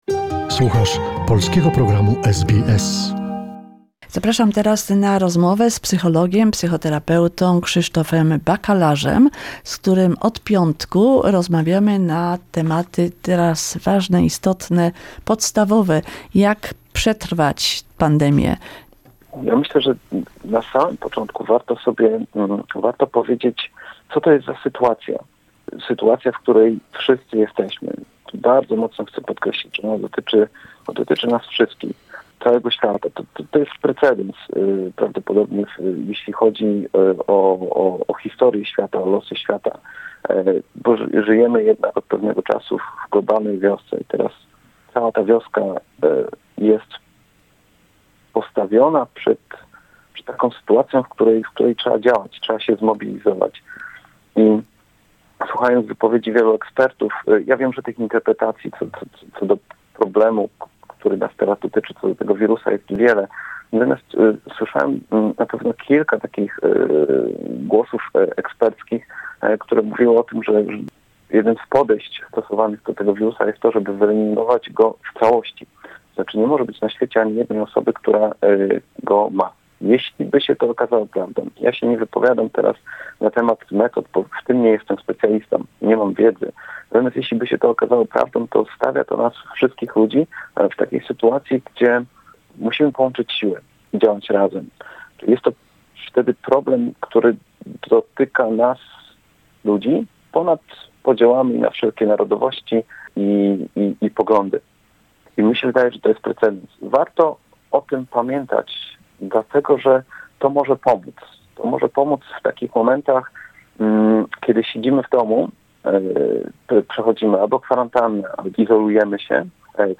The second conversation is about how too keep at bay negative emotions and negative news.